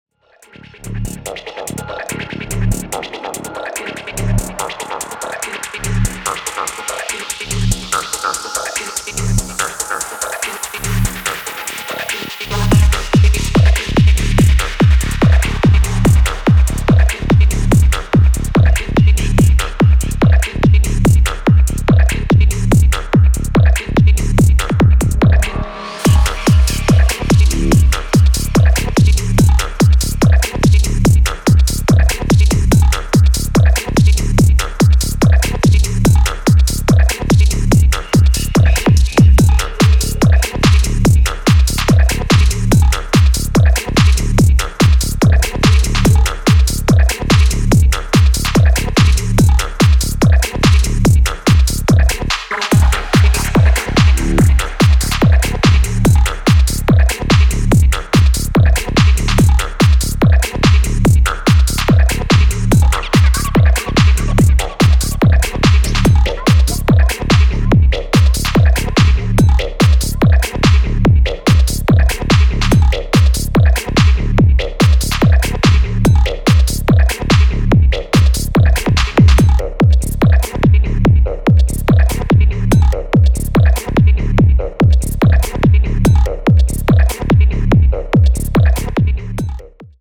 強靭なコシの弾力キックとパーカッシヴでシズル感溢れるシンセのテクスチャーに痺れるミニマル・テック・トランス